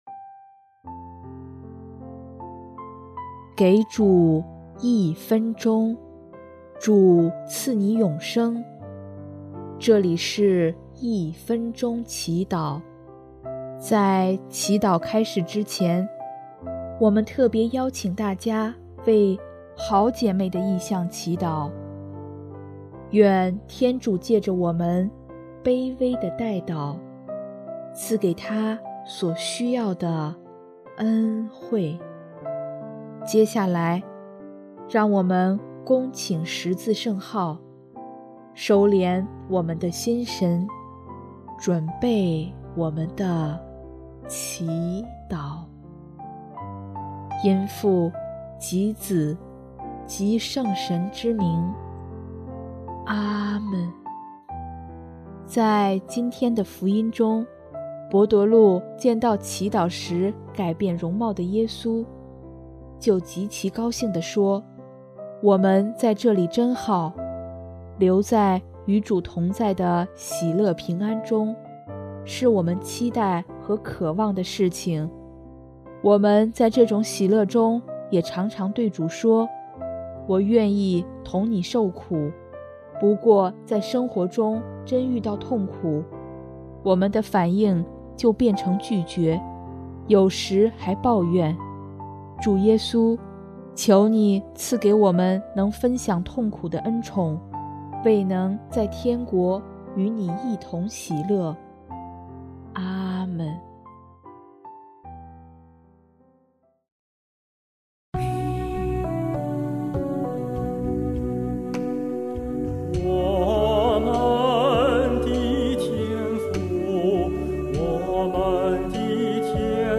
【一分钟祈祷】|3月16日 与主同苦，与主同乐
音乐： 第四届华语圣歌大赛参赛歌曲《天主经》